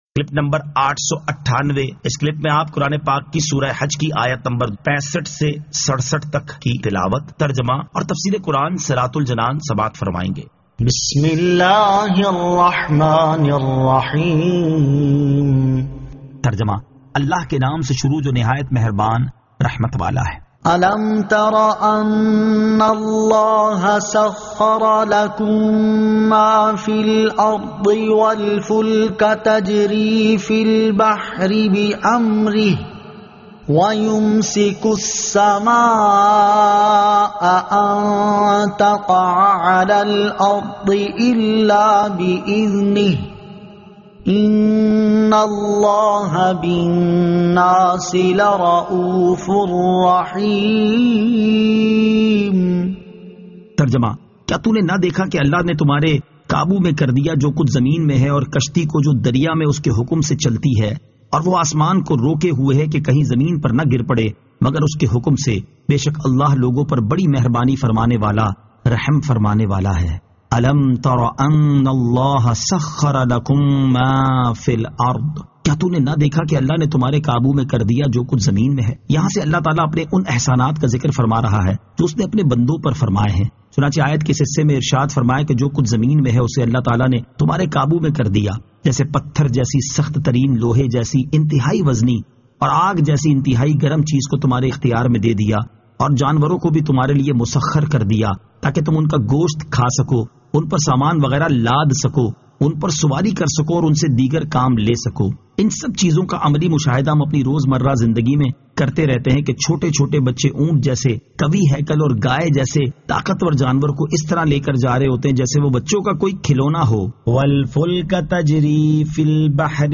Surah Al-Hajj 65 To 67 Tilawat , Tarjama , Tafseer